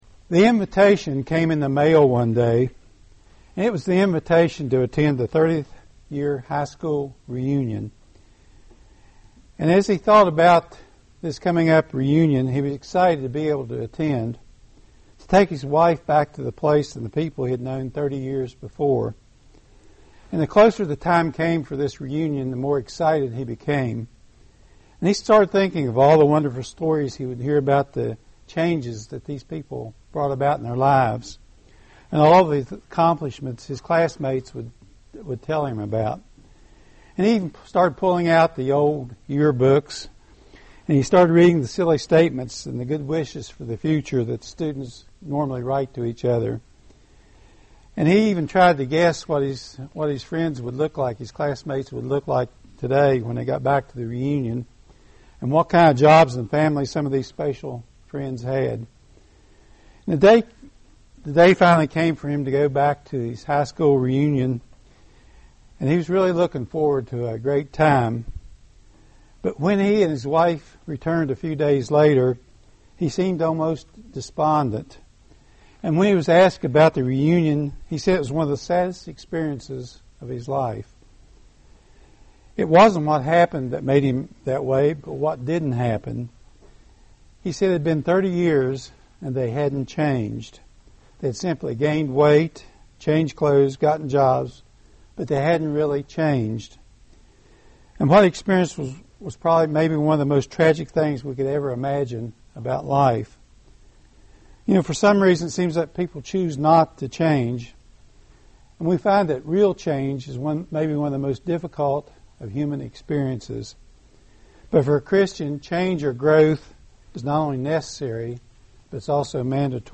Given in Roanoke, VA
UCG Sermon Studying the bible?